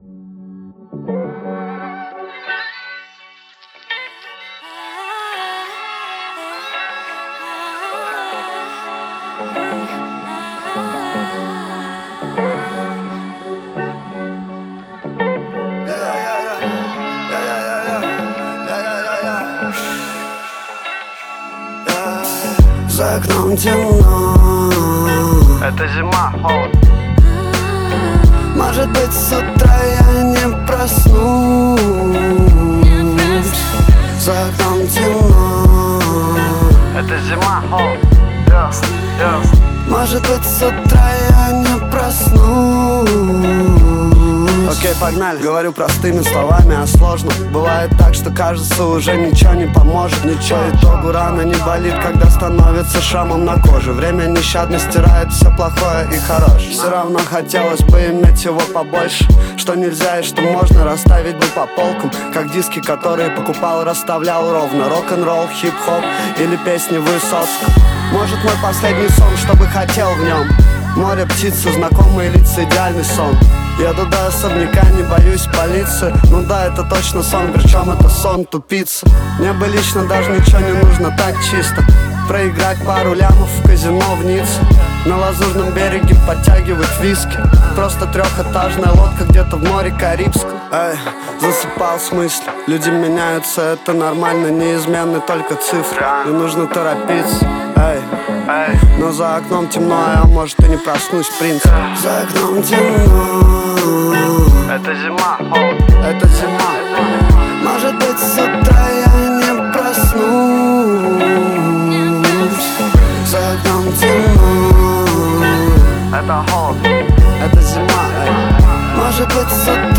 Категория Рэп